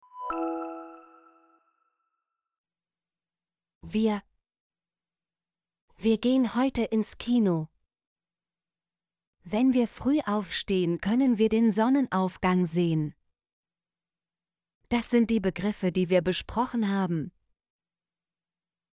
wir - Example Sentences & Pronunciation, German Frequency List